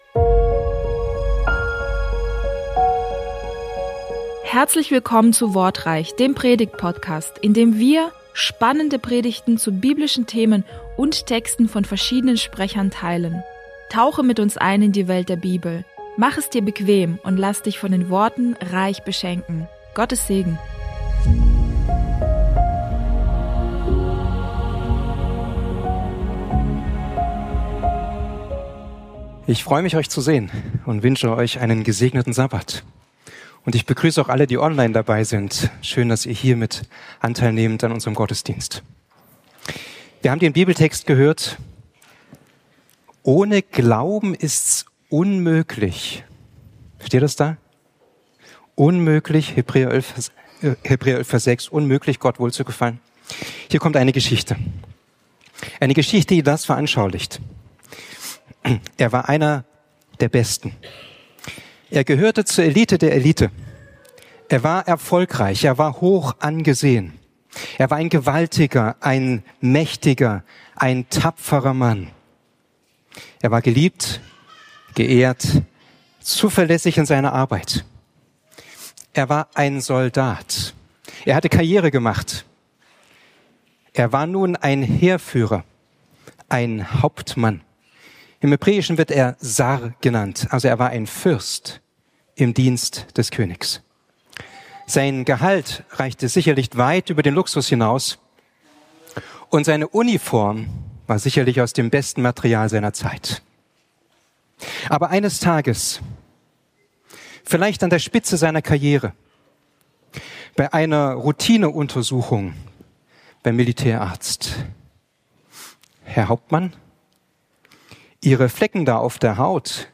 Hier hörst du Predigten aus Bogenhofen von unterschiedlichen Predigern, die dich näher zu Gott bringen und deinen Glauben festigen.